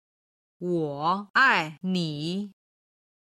今日の振り返り！中国語発声
01-woaini.mp3